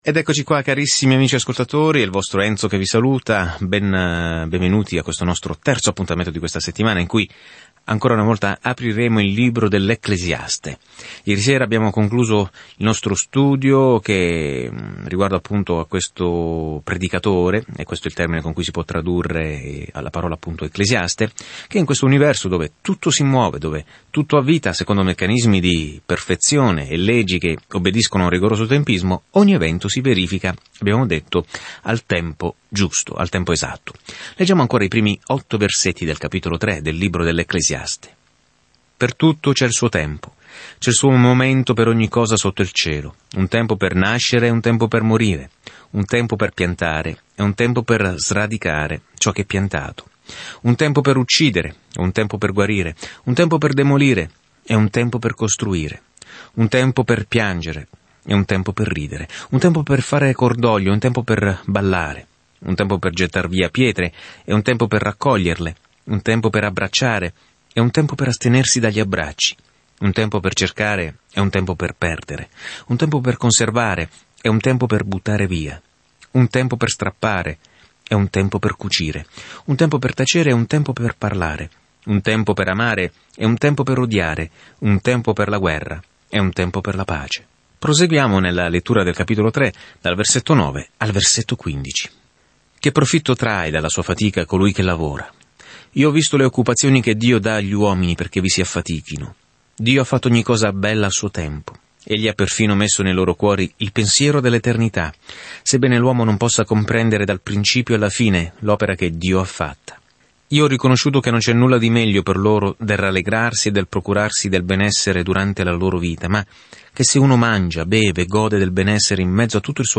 Scrittura Ecclesiaste 3 Ecclesiaste 4 Giorno 2 Inizia questo Piano Giorno 4 Riguardo questo Piano Ecclesiaste è una drammatica autobiografia della vita di Salomone quando cercava di essere felice senza Dio. Viaggiando ogni giorno attraverso l'Ecclesiaste ascolti lo studio audio e leggi versetti selezionati della parola di Dio.